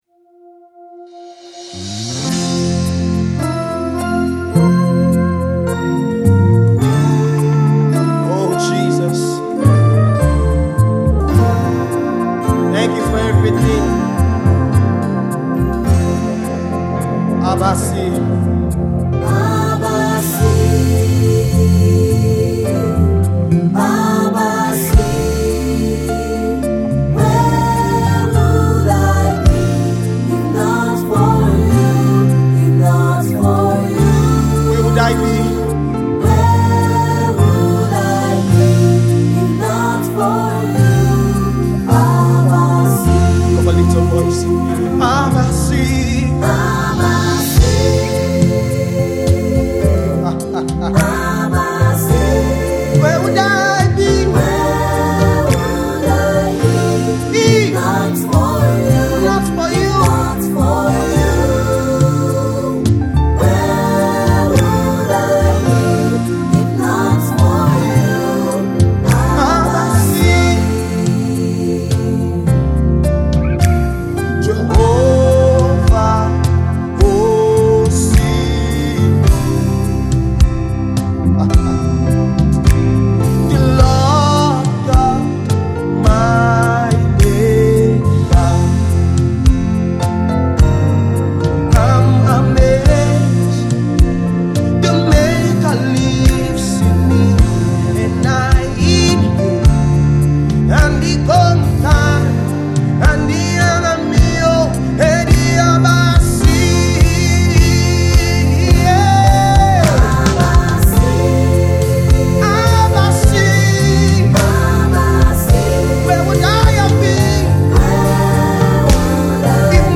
spirit-filled gospel